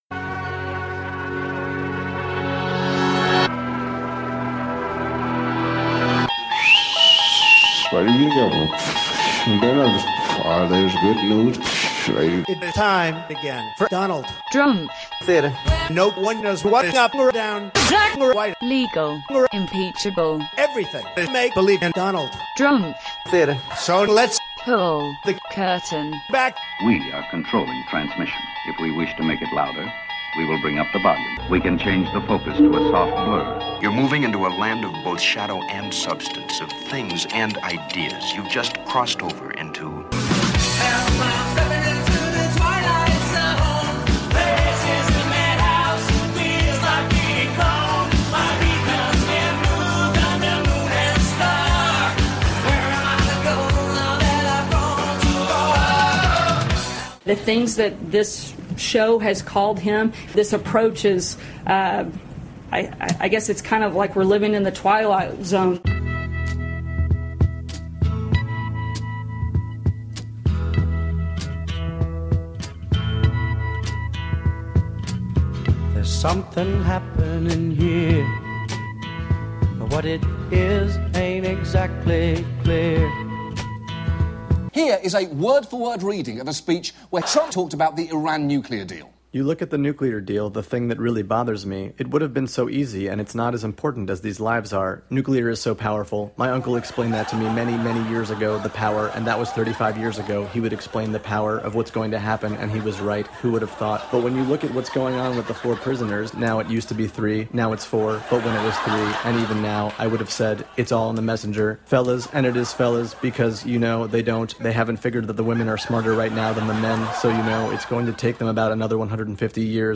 "Donald Drumpf Theatre" is an original radio theatre show, with most sounds culled from the previous week, about a fictional, theatrical presidency. Contradictions tell the story, with songs, skits, and clips from political news shows and late-night comedy routines. The definition of "radio theatre" is stretched here, with an updated Dickie Goodman and/or Richard Foreman-meets-mashup style sometimes, and a more straightforward take other weeks.